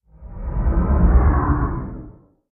Minecraft Version Minecraft Version 25w18a Latest Release | Latest Snapshot 25w18a / assets / minecraft / sounds / mob / guardian / elder_idle1.ogg Compare With Compare With Latest Release | Latest Snapshot
elder_idle1.ogg